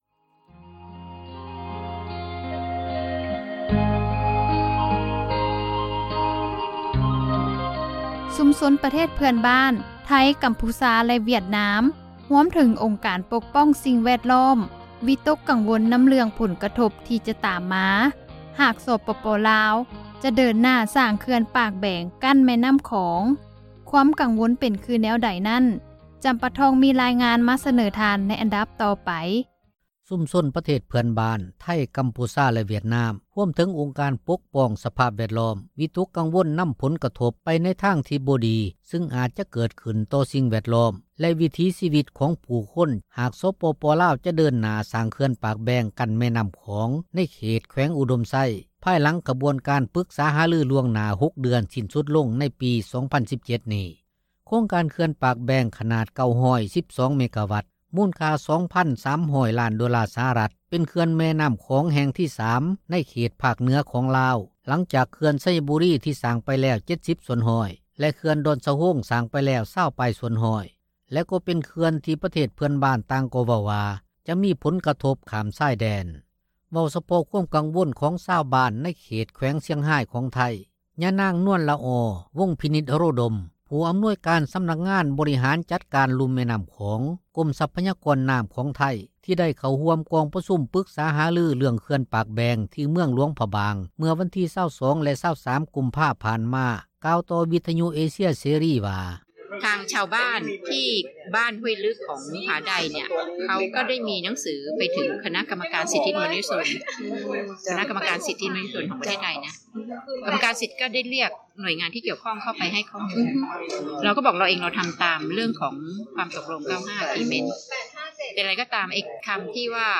ມີຣາຍງານ ມາສເນີທ່ານ